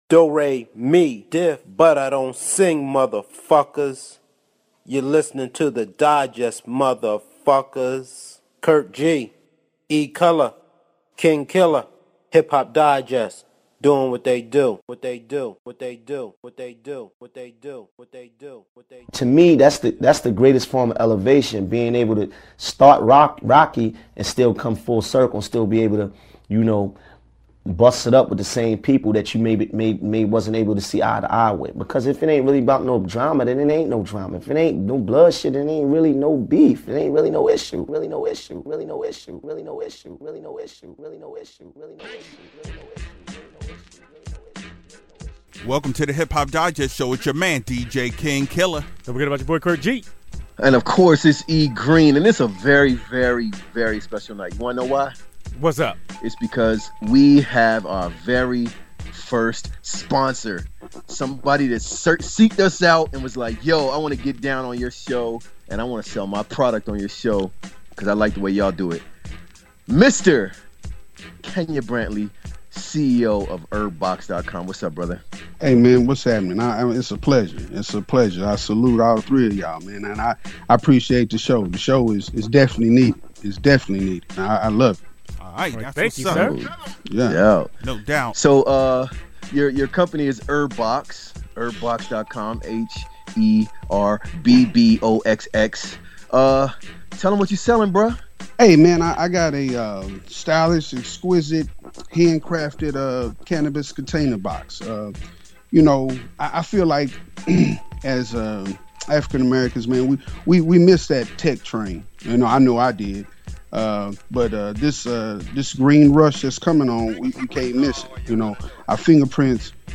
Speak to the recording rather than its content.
We finally picked up a sponsor for the Music Segment near the end of the show!